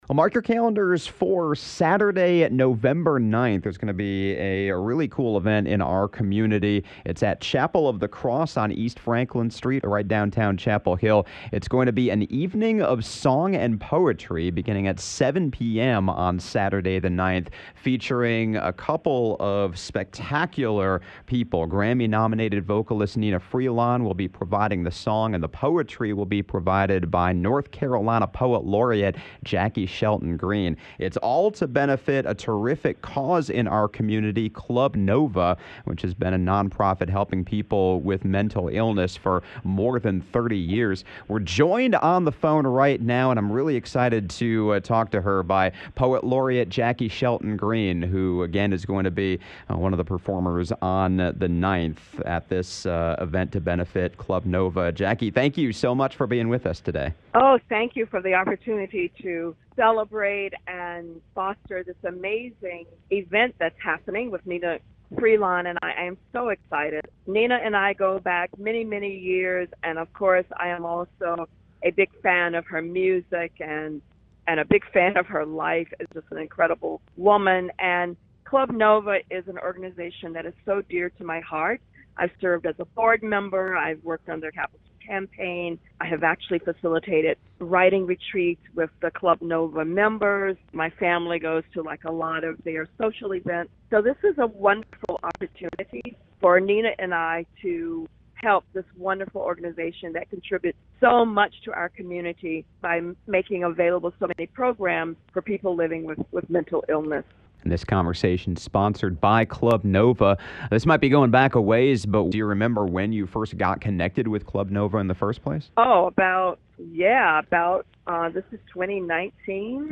Conversation sponsored by Club Nova.